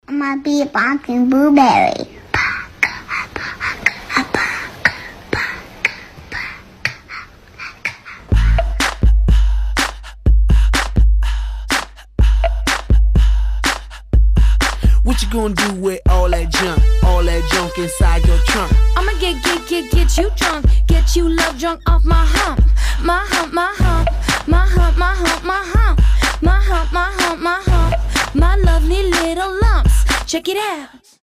• Качество: 320, Stereo
RnB
детский голос
смешные
Mashup
Мэшап родом из Тик Тока